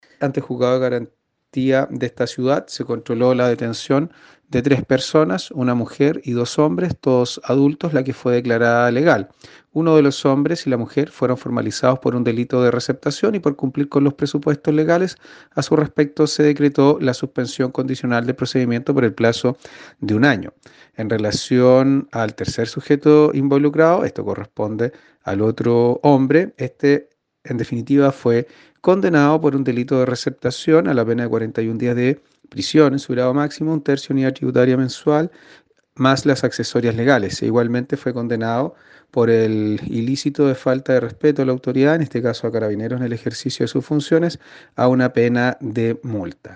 El fiscal de Castro Fernando Metzner sostuvo tras la audiencia de control de la detención que todos fueron imputados por el delito de receptación, pero a dos de ellos se le aplicó la suspensión del procedimiento por un año; en cambio, el tercer sujeto fue acusado además de maltrato de obra a Carabineros.